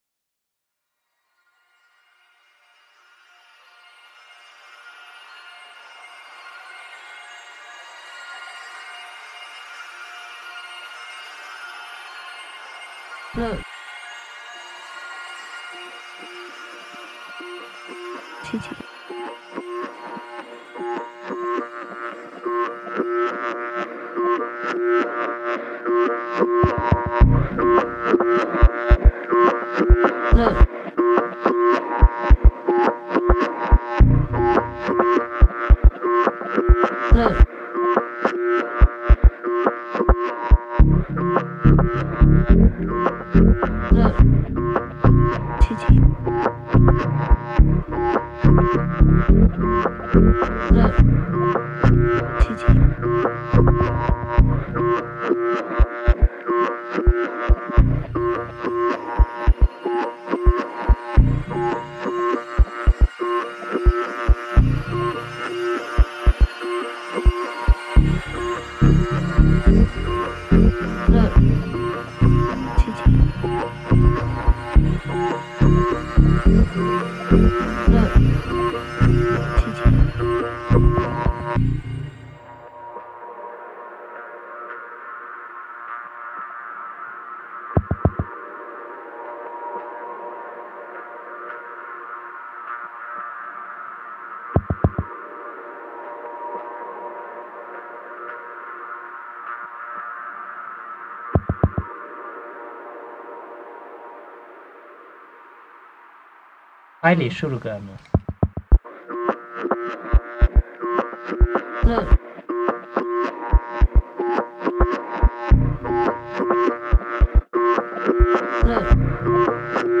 For this project I challenged myself to create every sound directly from the original recording. Drum hits, textures, bass and even a cowboy guitar were all manipulated extracts from the sample. The recording contains vocal interjections which I reused as percussive elements.
The jaw harp has a rich timbre and a compelling rhythmic drive, but it often required careful processing to soften harsh metallic tones.
Man playing bināyā (jew's harp) reimagined